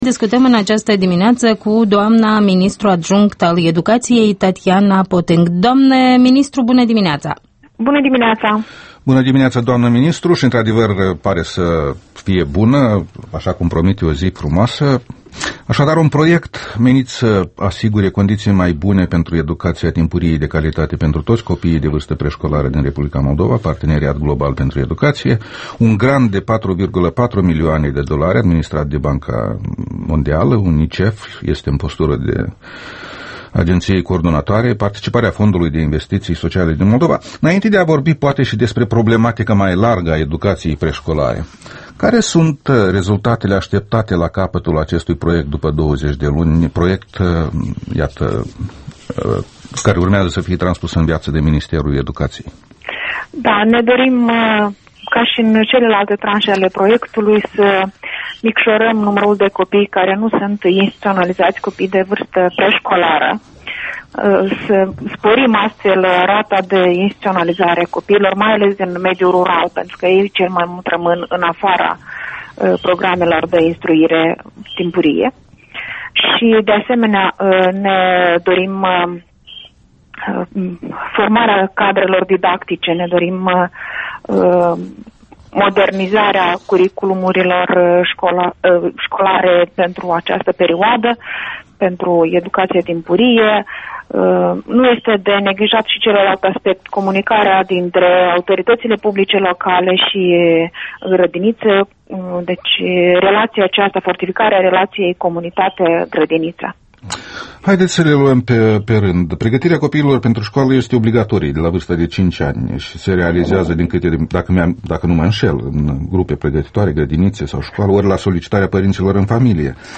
Interviul dimineții la Europa Liberă: cu Tatiana Potîng, ministru adjunct al învățămîntului